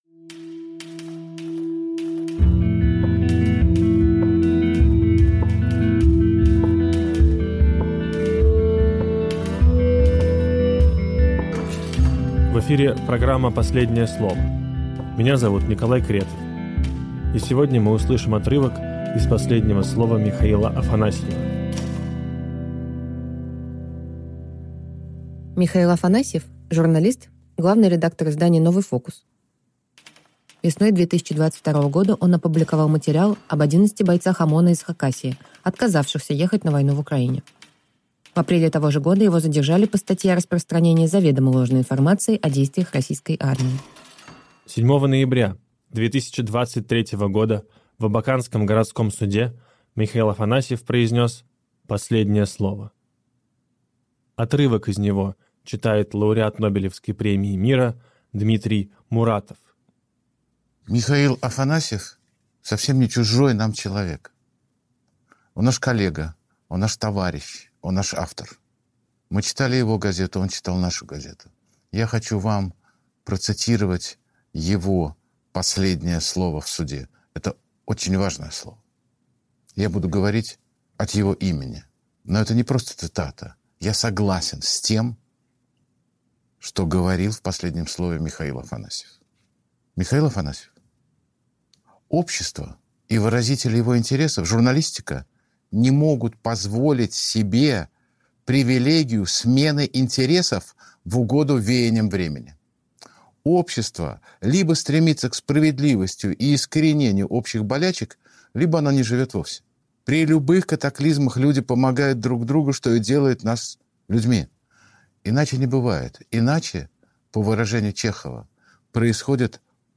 Читает Дмитрий Муратов